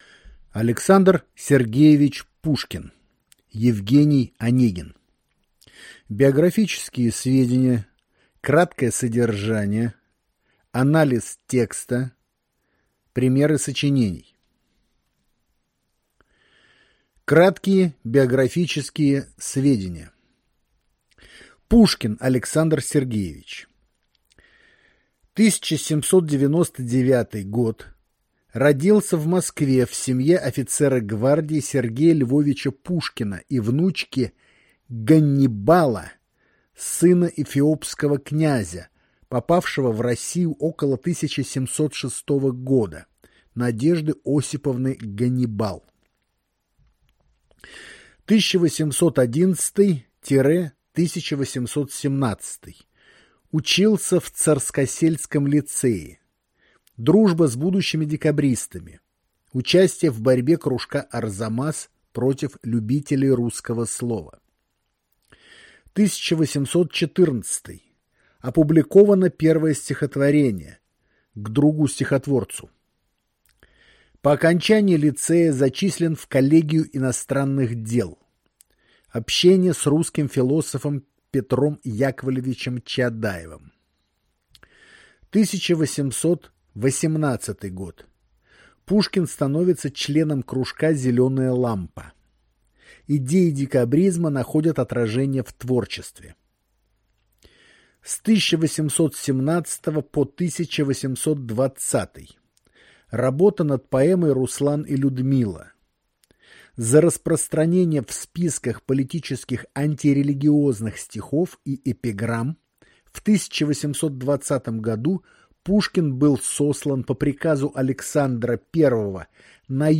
Аудиокнига А. С. Пушкин «Евгений Онегин». Биографические сведения. Краткое содержание. Анализ текста. Примеры сочинений | Библиотека аудиокниг